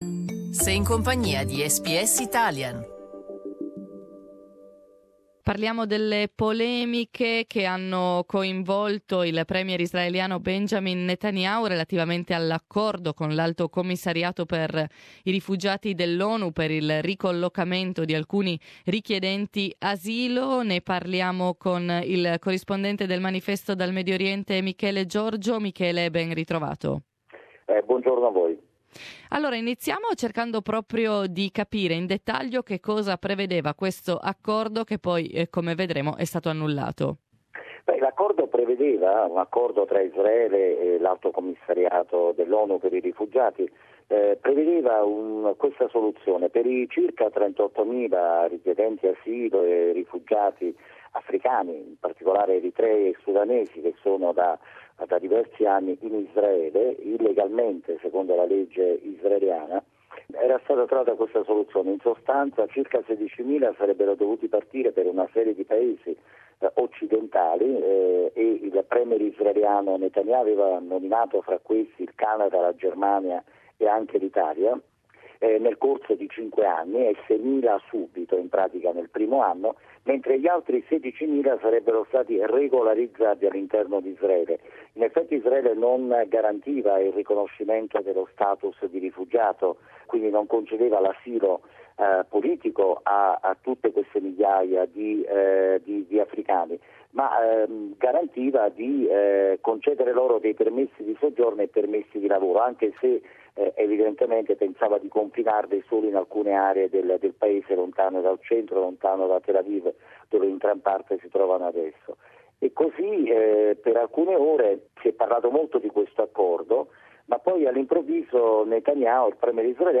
Italian journalist
reports from Jerusalem.